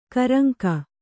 ♪ karaŋka